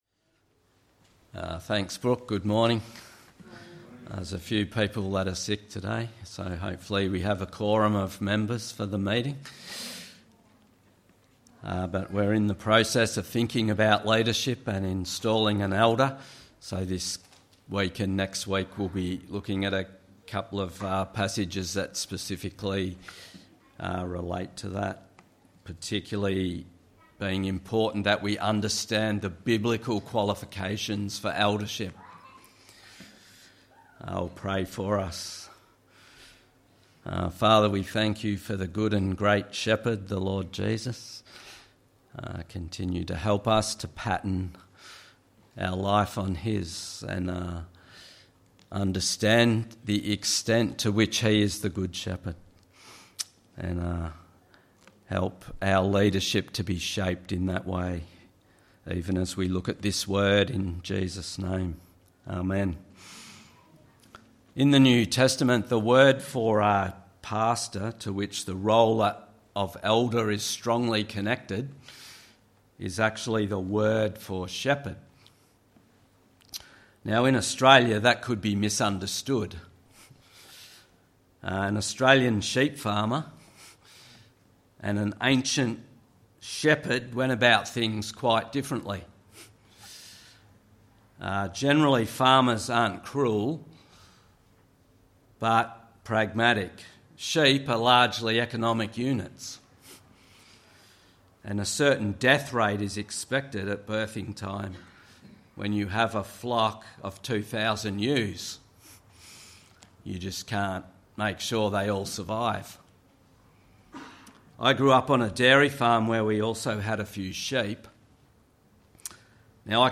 Sermon: 1 Peter 5:1-14